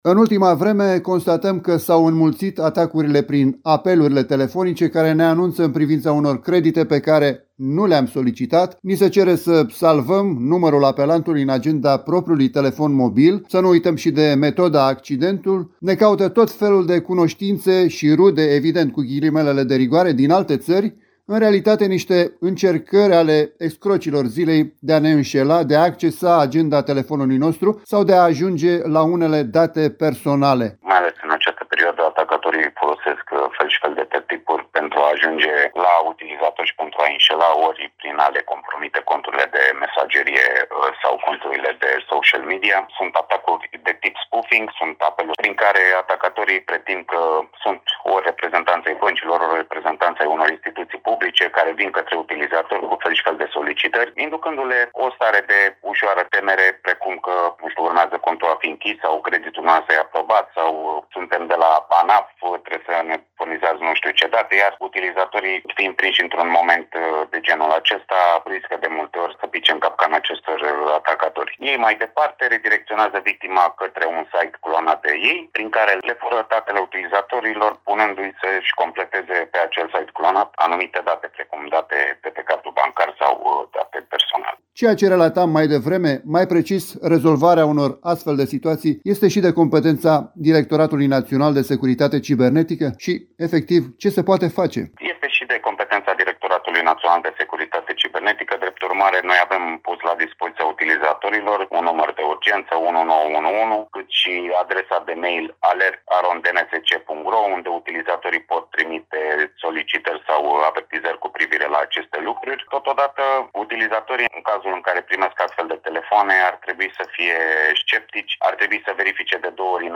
AUDIO | S-au înmulțit atacurile prin apeluri telefonice. Interviu